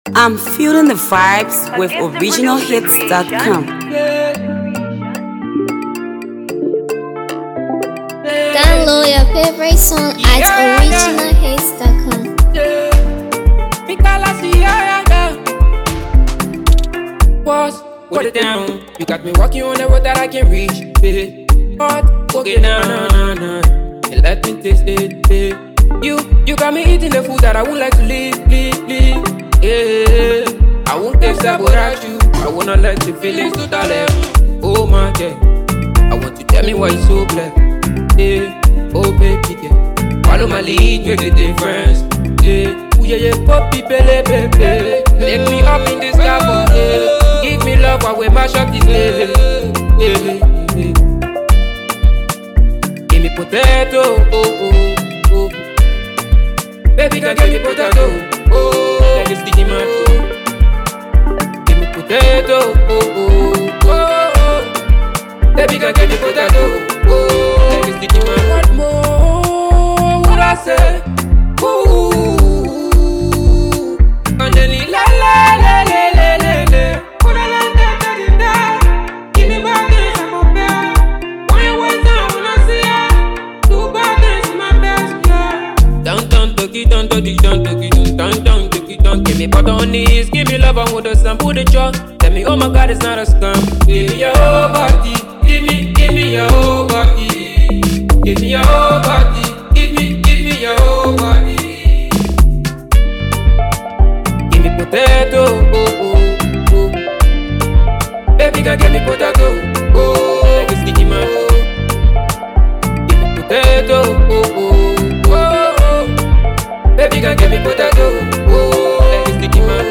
It’s a nice melody jam